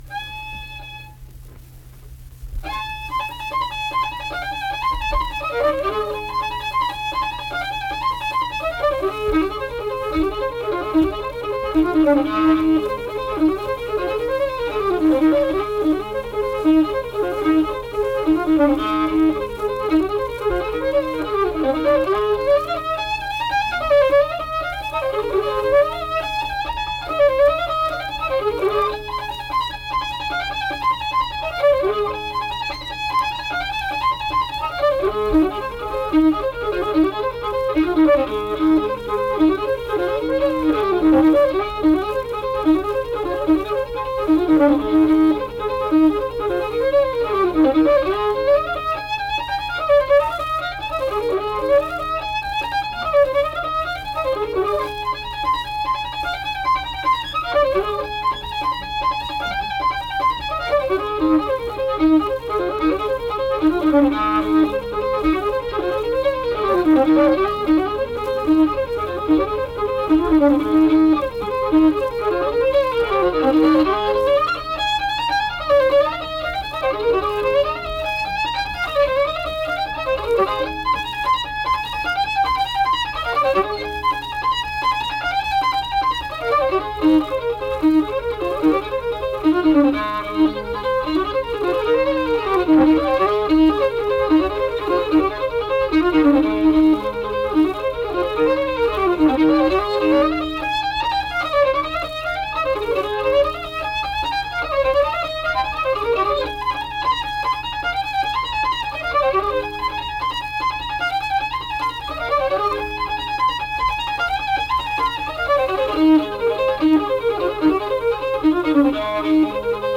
Leather Britches - West Virginia Folk Music | WVU Libraries
Unaccompanied fiddle music and accompanied (guitar) vocal music
Instrumental Music
Fiddle
Braxton County (W. Va.)